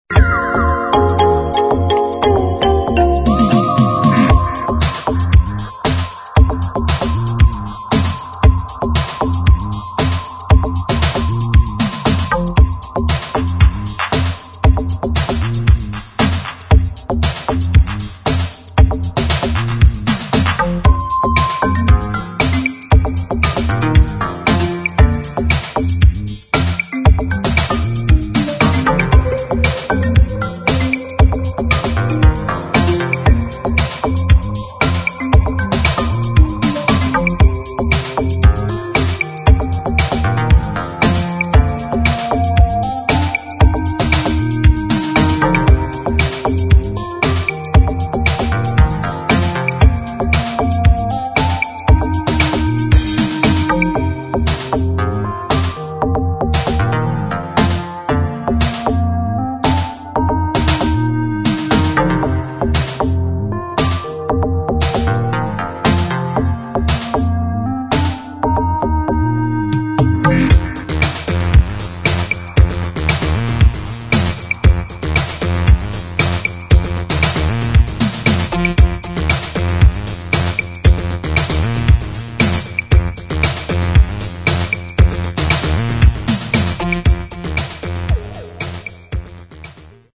[ HOUSE / COSMIC ]